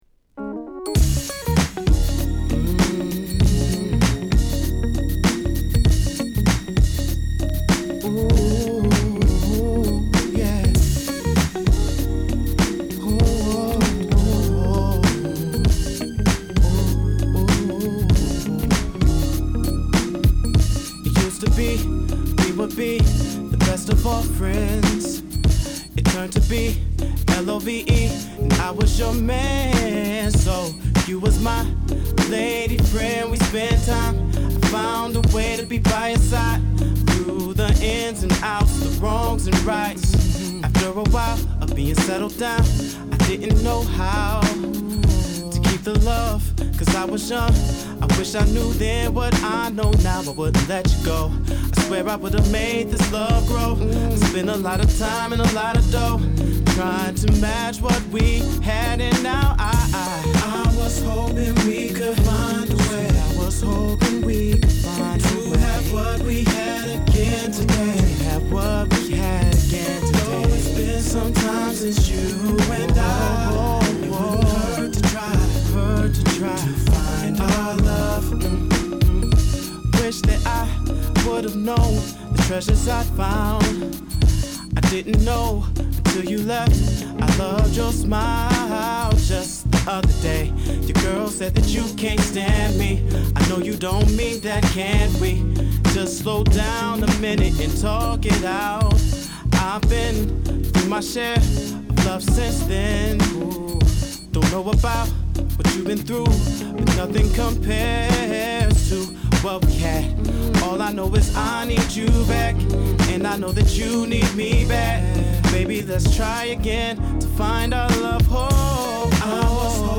デトロイト出身のシンガーソングライター